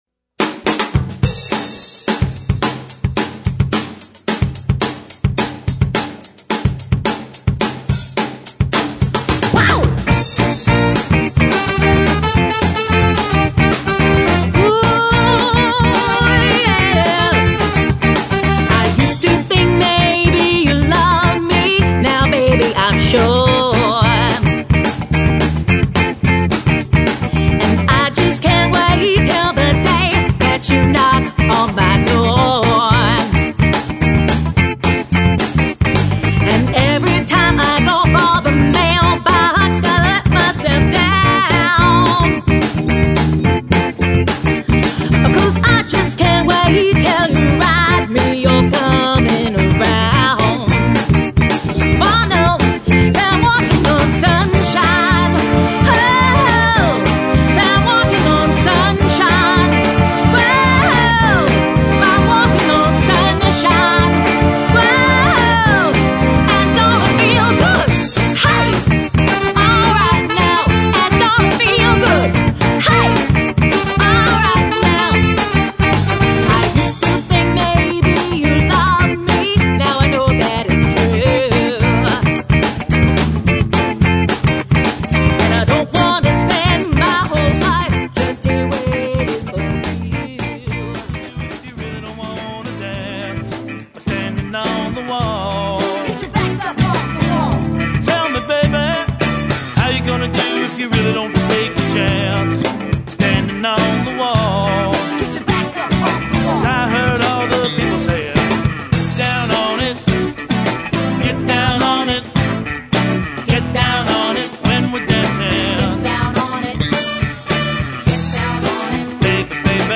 JAZZ
DANCE, R&B, DISCO, ROCK, CLASSIC ROCK